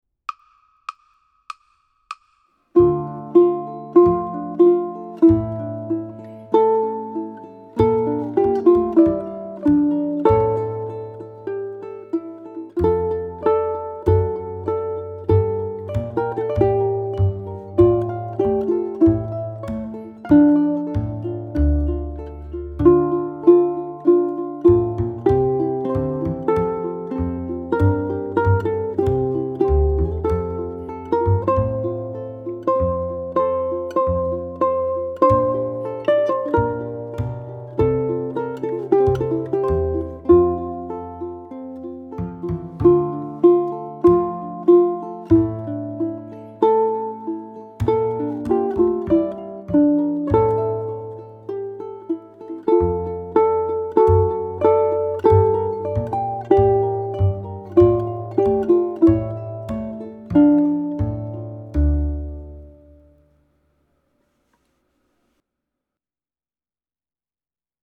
Andante Op. 31 No. 4 was recorded with a Kremona Coco tenor. The melody was embellished slightly and improvised fills were added here and there. Percussion was provided with two frogs (guiro) and a casaba.
ʻukulele
An arpeggio accompaniment complements the slow and lyrical melody of Andante perfectly.